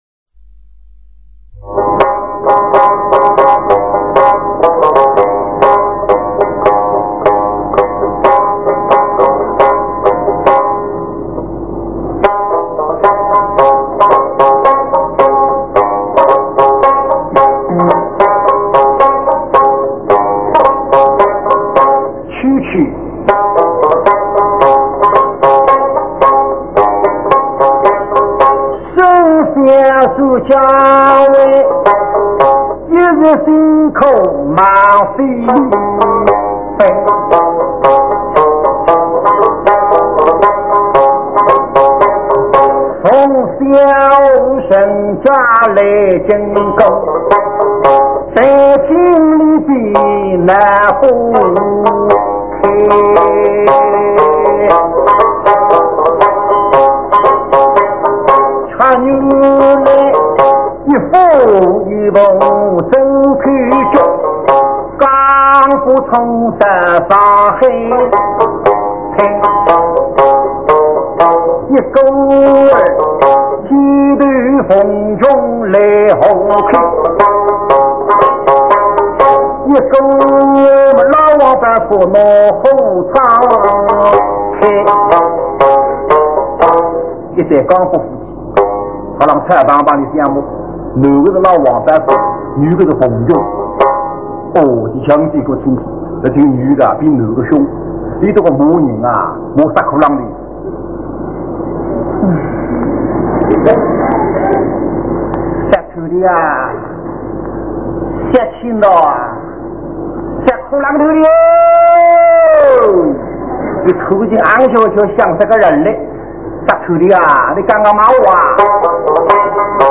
穩而不躁，徐徐唱來，哪怕表現一個吵架的場景，也是穩穩當當，篤篤悠悠，能夠真正體現出彈詞的美來。
分別有說書人的旁白和唱段，亦人江北夫妻的對白與唱段
勒浪——「在」的意思，說書人的旁白，用的是蘇白，即蘇州話。
，演員要快速切換人物，讓人聽得極是過癮。